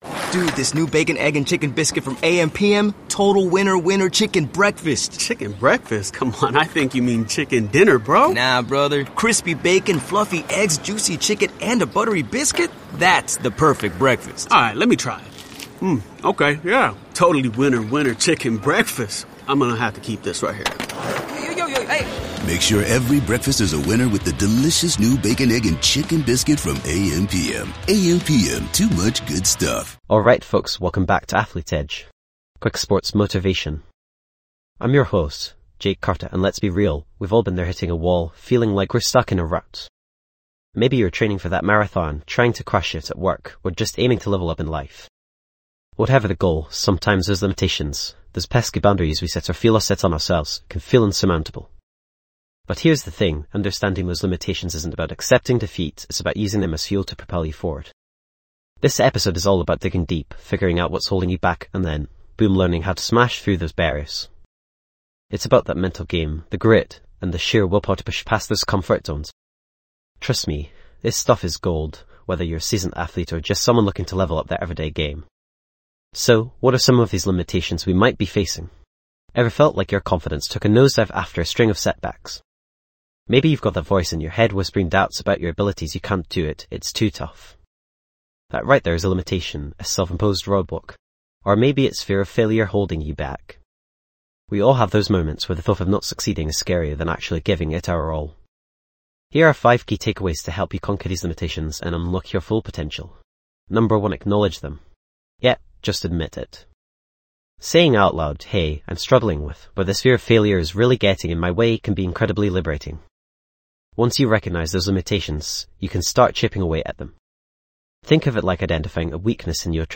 Sports & Recreation Coaching & Instruction Motivational Talks & Self-Improvement
This podcast is created with the help of advanced AI to deliver thoughtful affirmations and positive messages just for you.